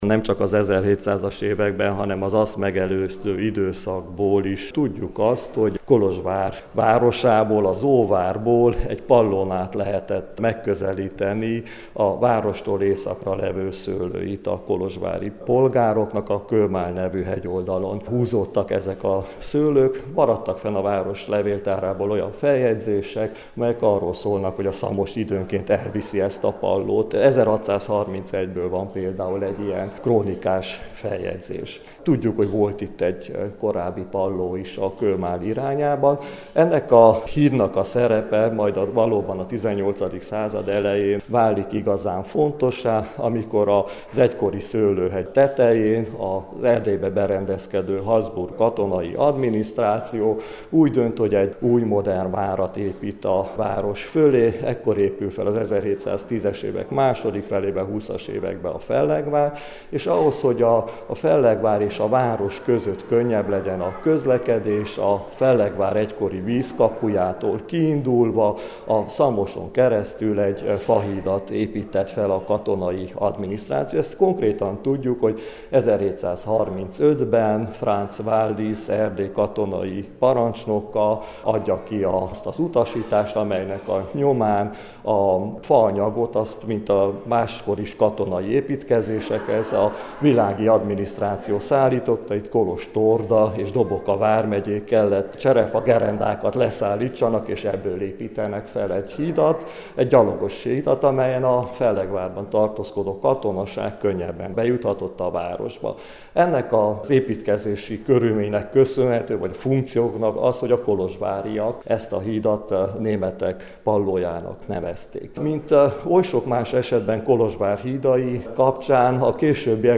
interjúja